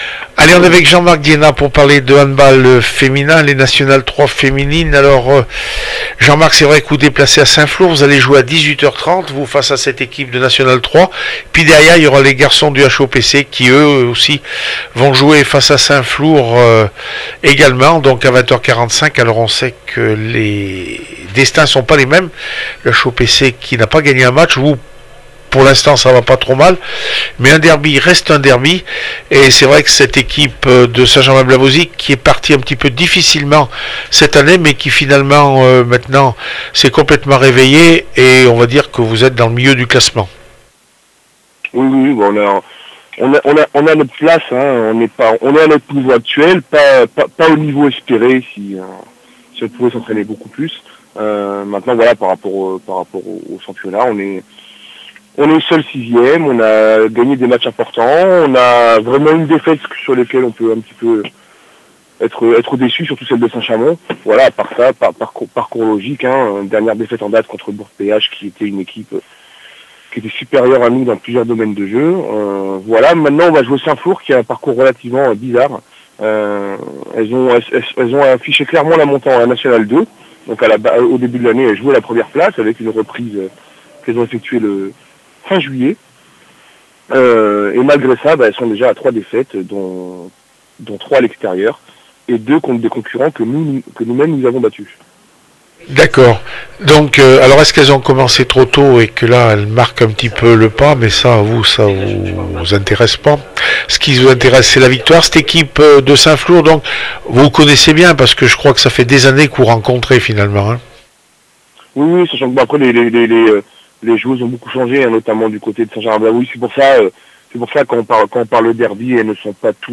4 décembre 2015   1 - Sport, 1 - Vos interviews, 2 - Infos en Bref   No comments